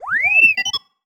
sci-fi_driod_robot_emote_07.wav